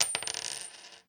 sounds_coin.ogg